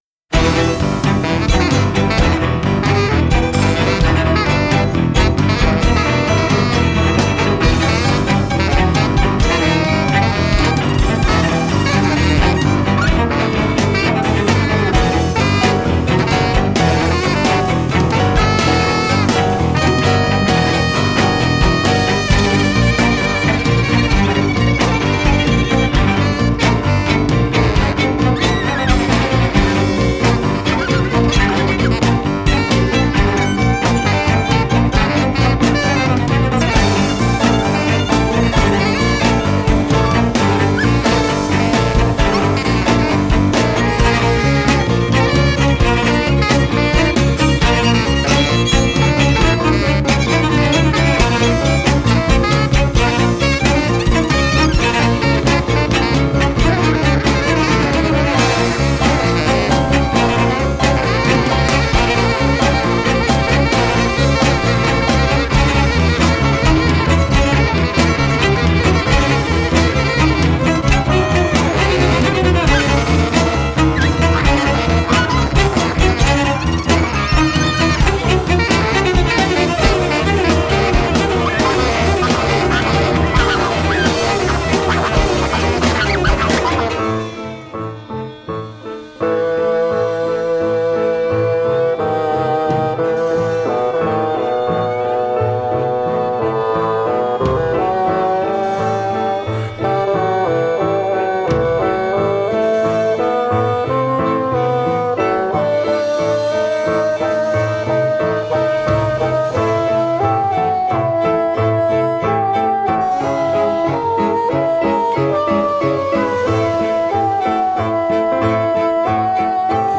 chamber music, rock, theatre and improvisation
Violin, cello, bassoon, saxes and accordion